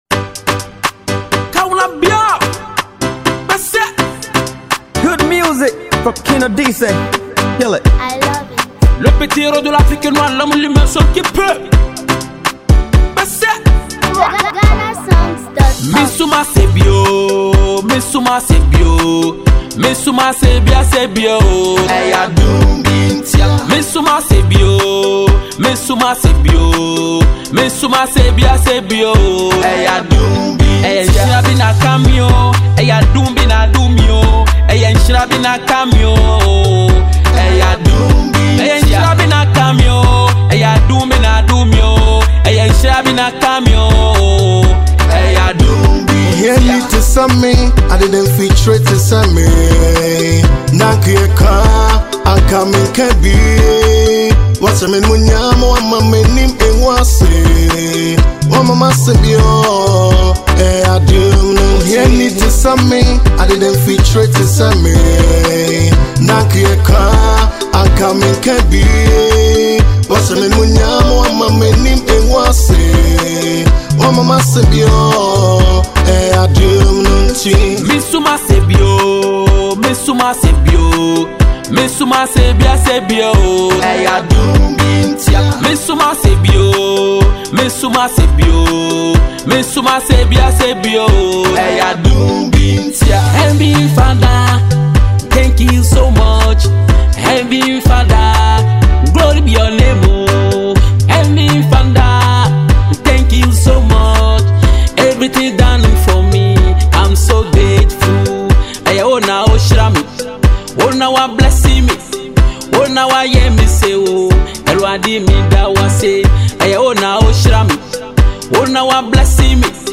Produced with rich melodies and uplifting vibes